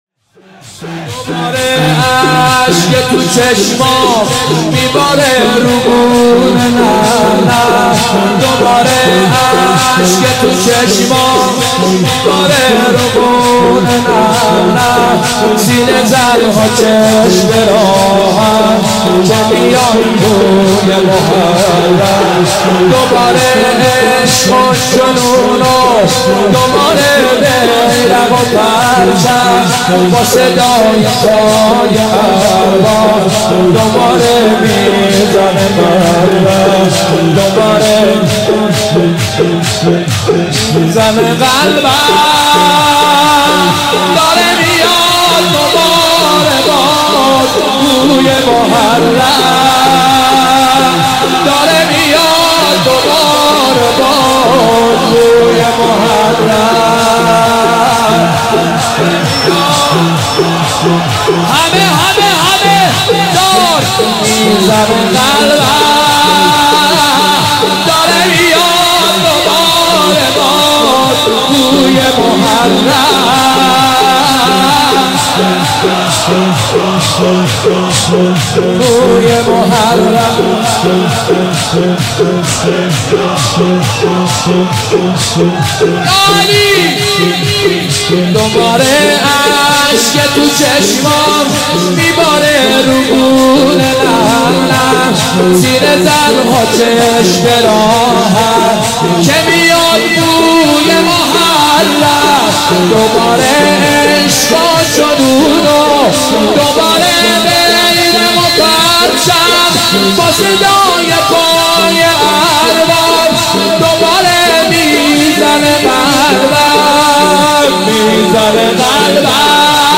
صوتی زیبا از مداح اهل بیت (ع)